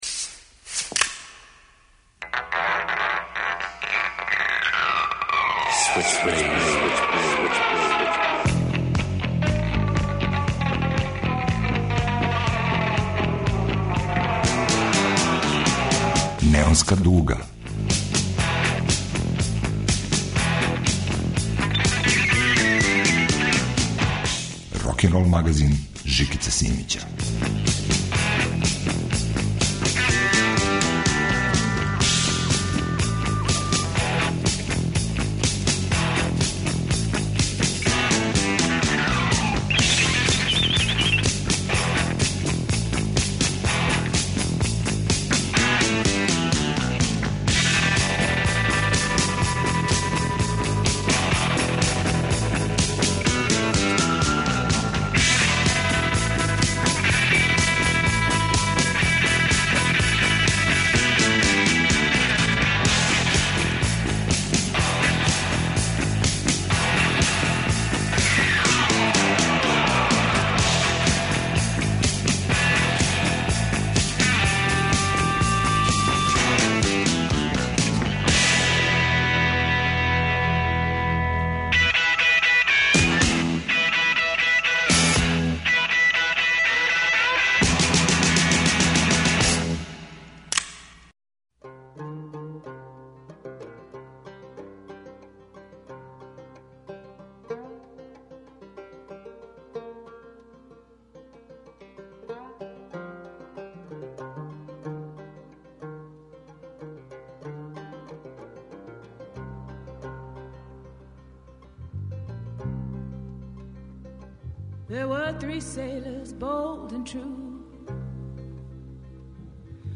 Рокенрол као музички скор за живот на дивљој страни. Вратоломни сурф кроз време и жанрове. Старо и ново у нераскидивом загрљају.